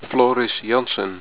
Pronounced